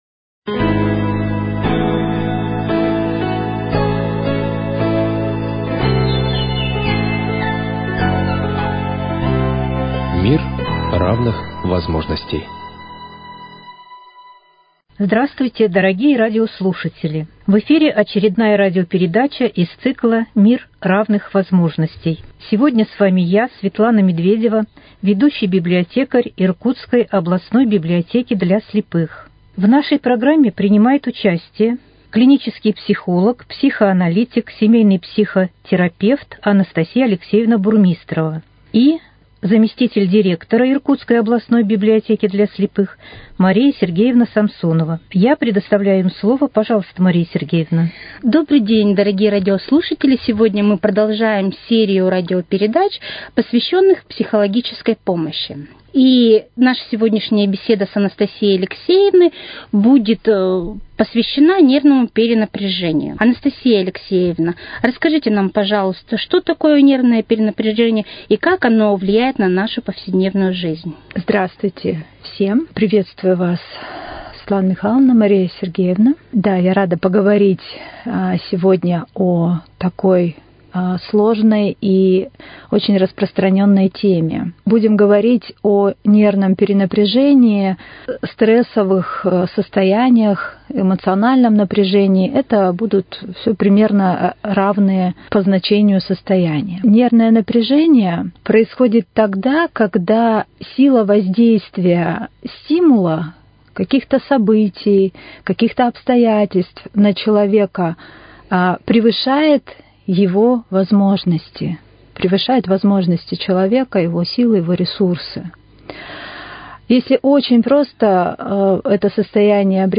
Общество